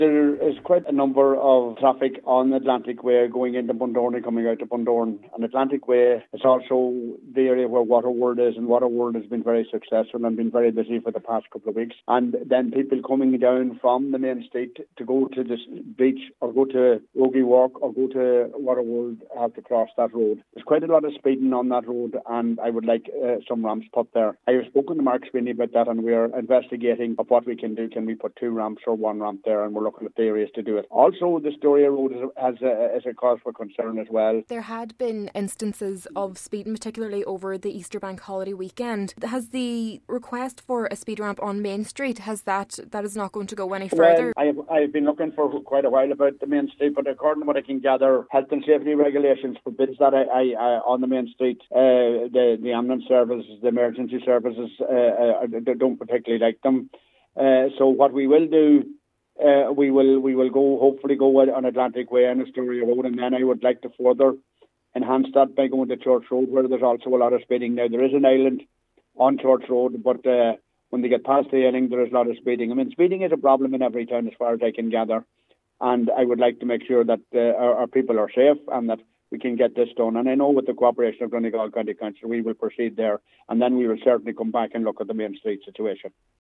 Cllr. Michael McMahon previous call for ramps on Main Street was denied due to health and safety reasons, but he says his priority is keeping people safe and an alternative solution will be found: